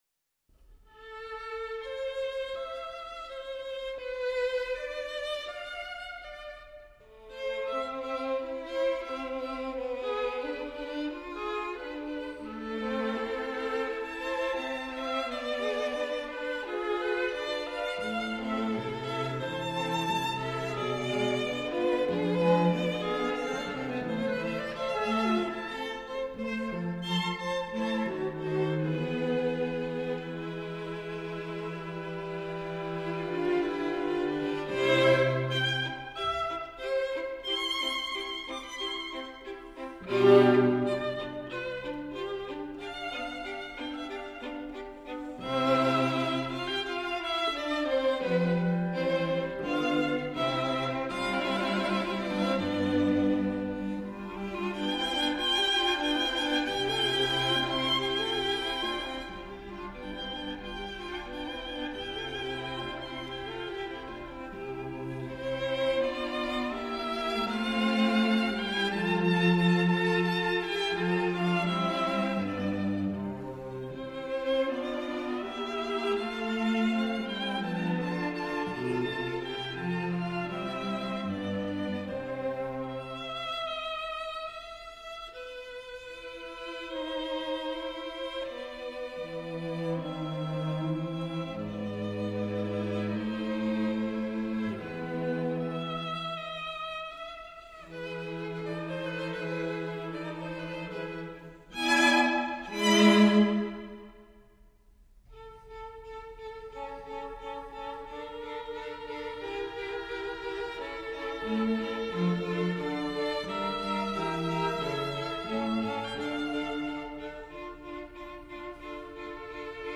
Andante un poco Allegretto